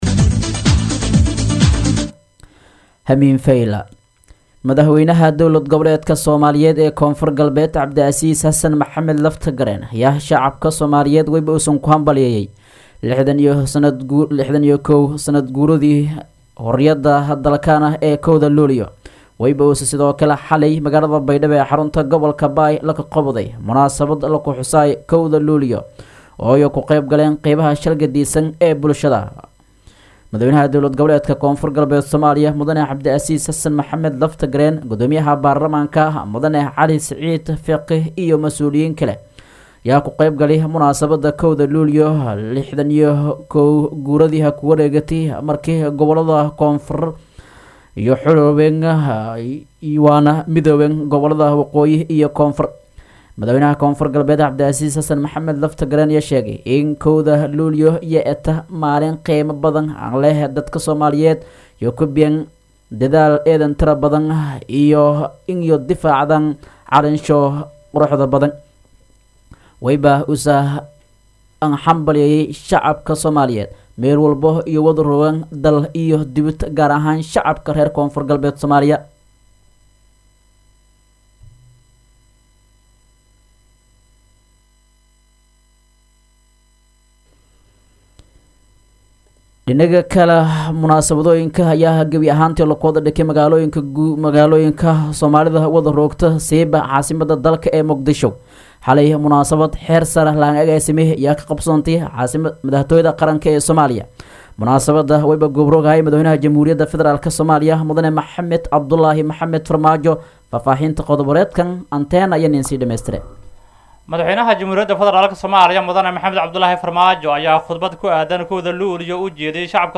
DHAGEYSO:- Warka Habenimo Radio Baidoa 1-7-2021